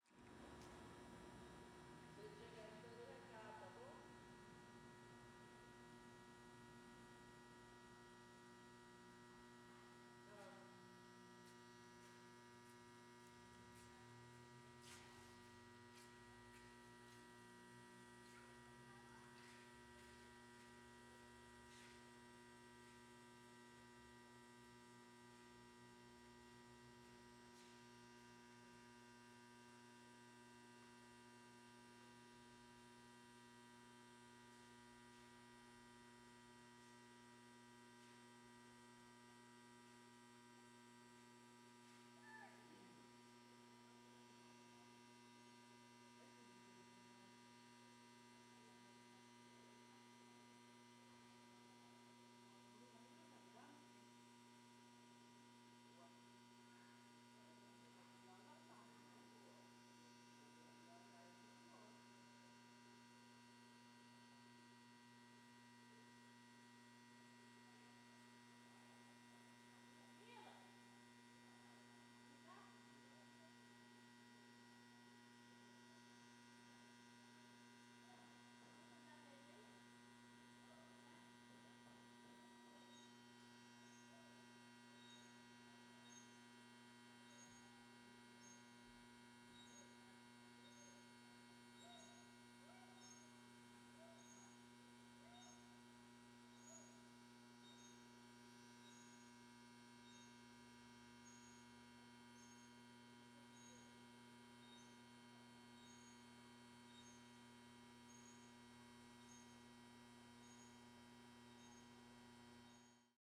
Arquivo de Transformador no poste - Coleção Sonora do Cerrado
CSC-03-025-GV - Ambiencia na Quadra de Esportes na Comunidade do Moinho na Chapada com Transformador muito ruidoso.wav